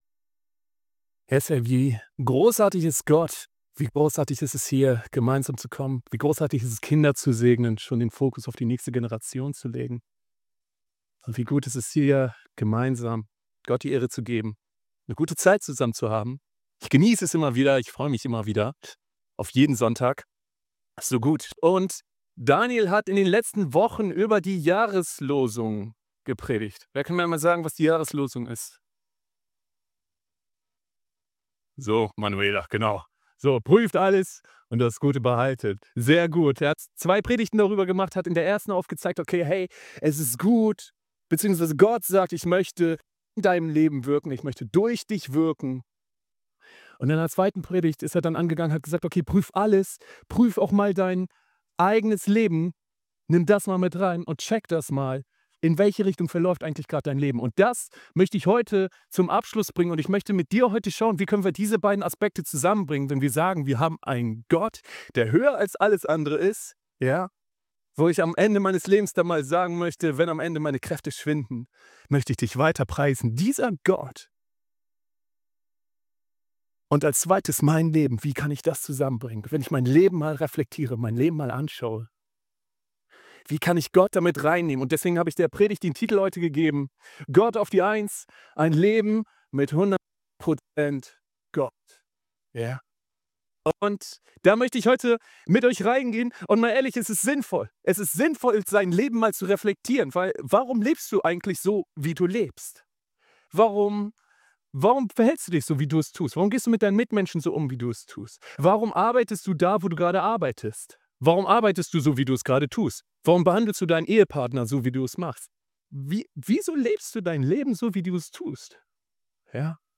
Gott auf die 1 - Ein Leben mit 100% Gott [Sonntagspredigt] ~ LikeAChrist Podcast